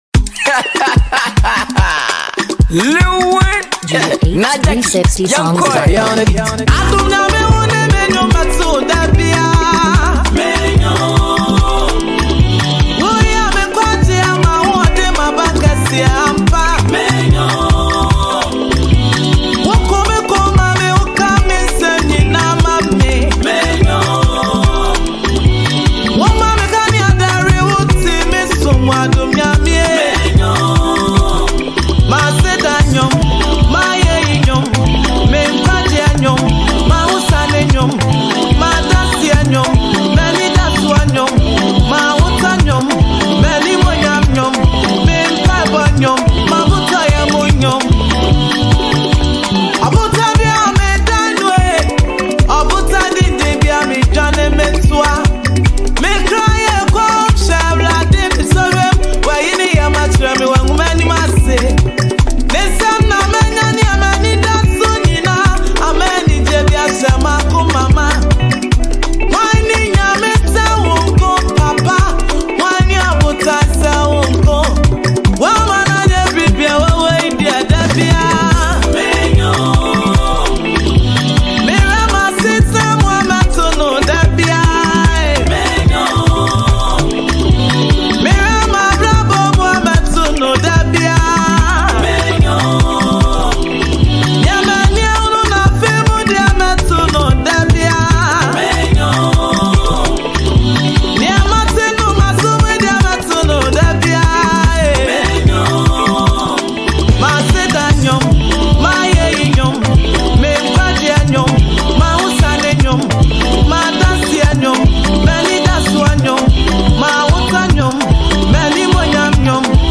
Ghana Music Gospel
her beautiful and unique voice